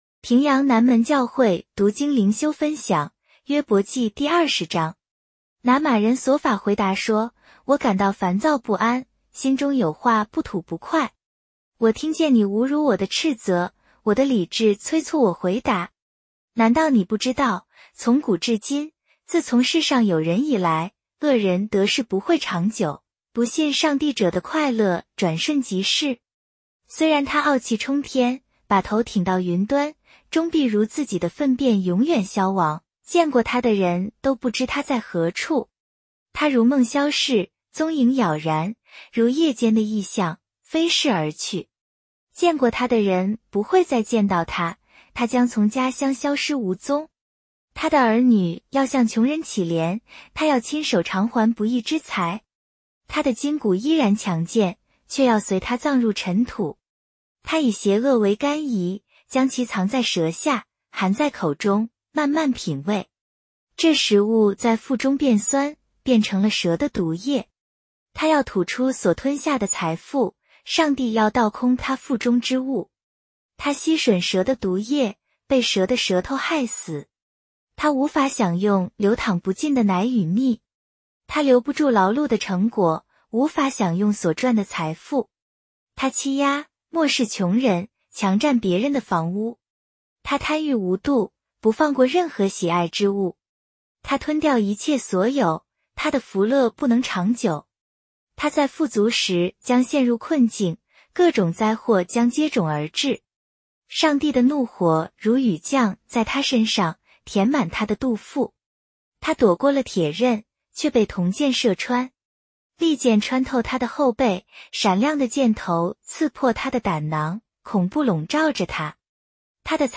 平阳话朗读——伯20